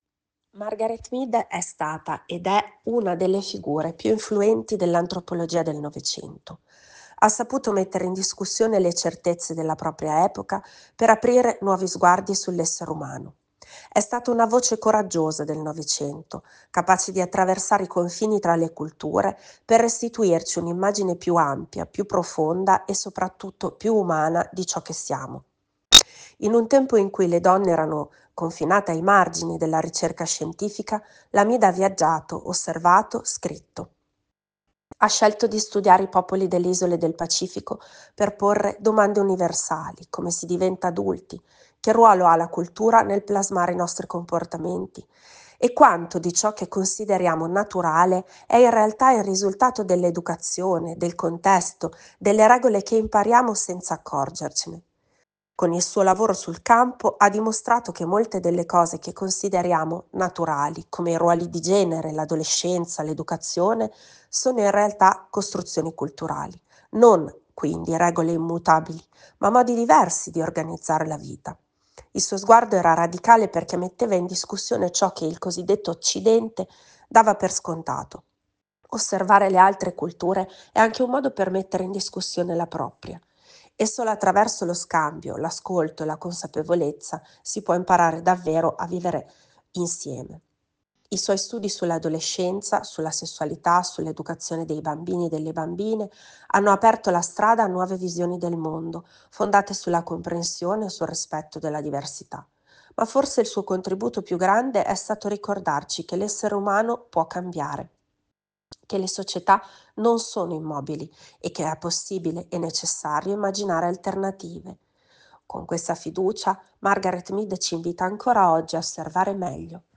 antropologa